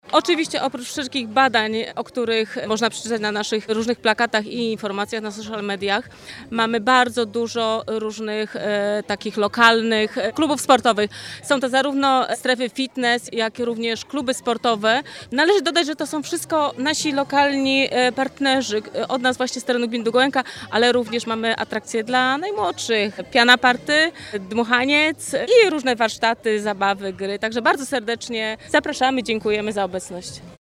Zdrowa Aktywna Długołęka [relacja z wydarzenia]